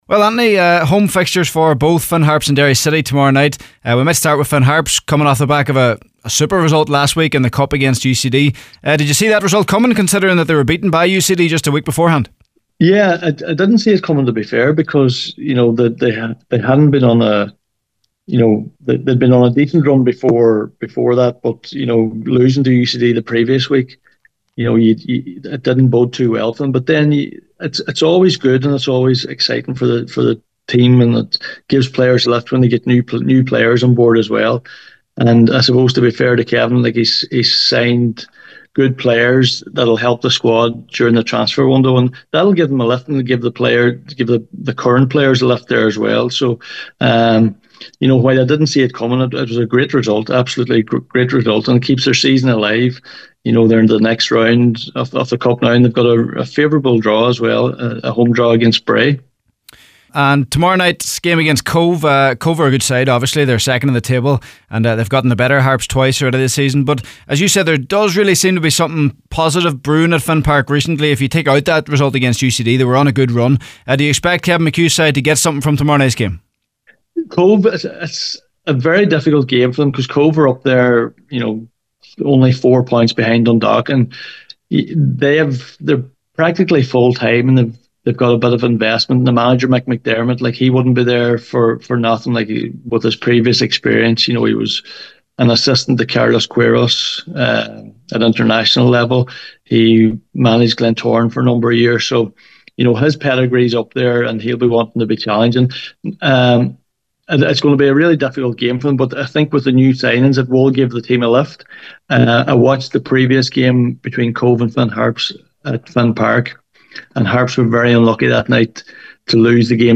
Talking Soccer